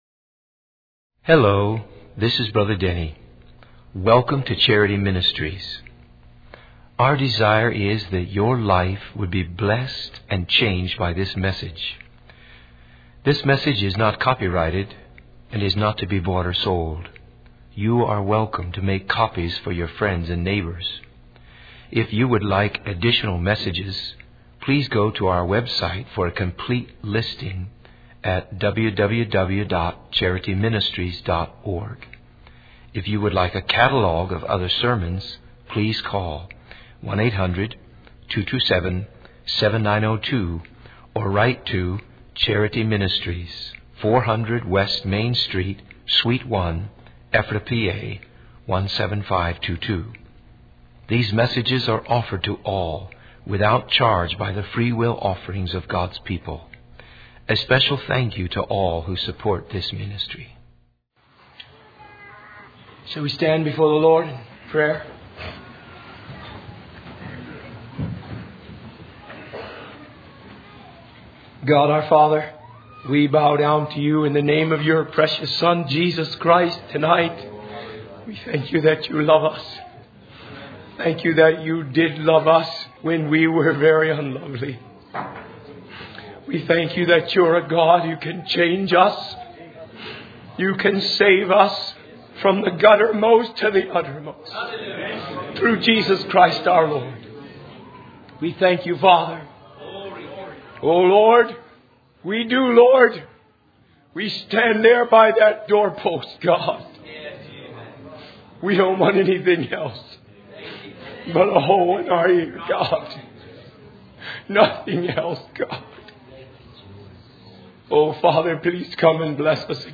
In this sermon, the speaker emphasizes the importance of building a strong relationship with one's children in order to establish authority. He highlights the need for parents to earn the respect and obedience of their children by walking with God and sacrificing for them.